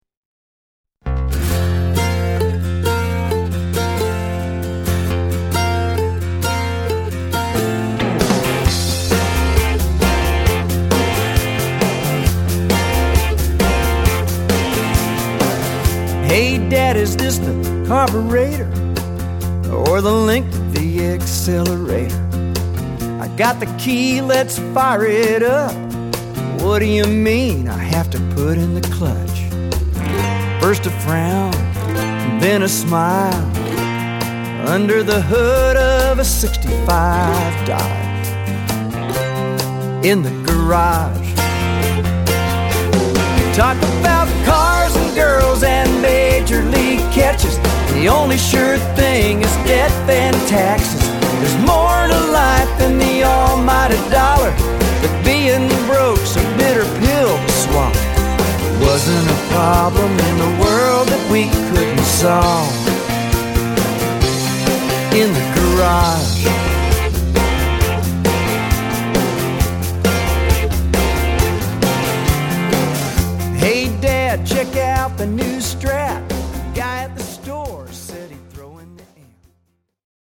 Americana with a touch of country rock